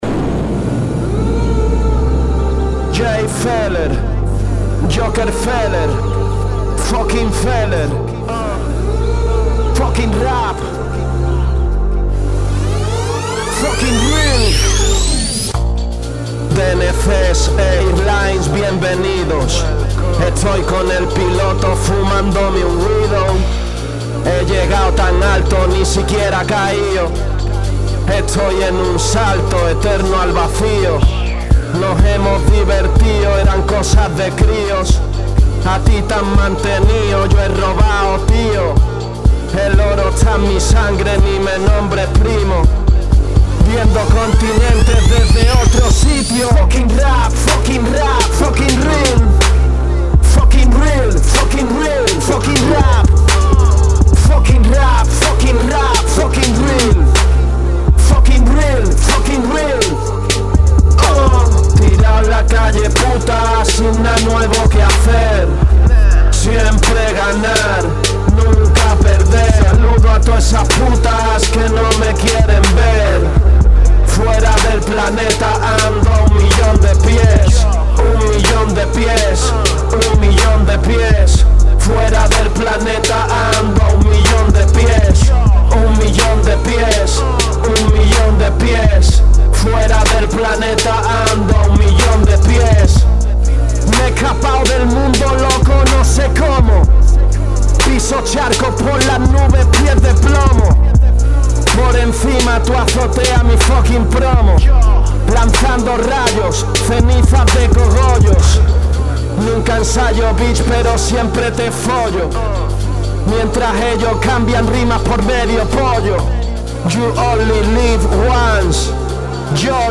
Está grabado, editado, mezclado y masterizado